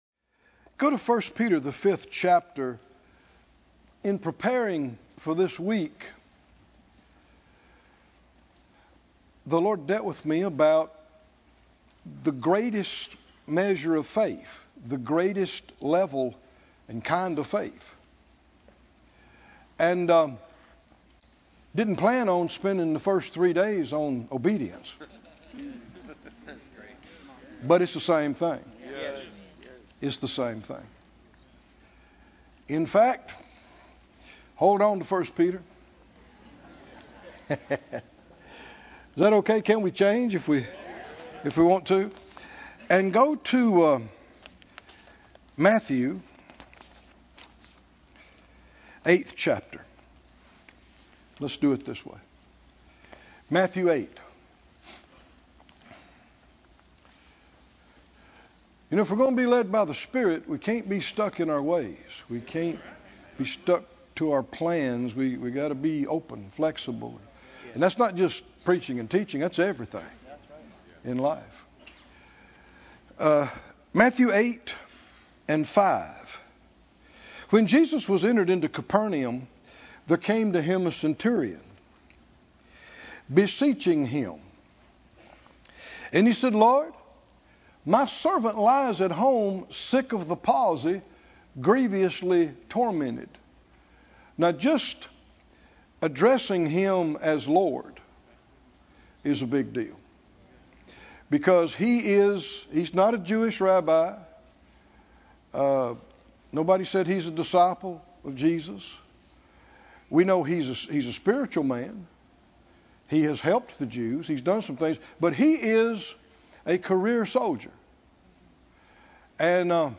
The Greater Faith Conference 2025: The Obedience Of Faith - Pt. 4 - Under The Mighty Hand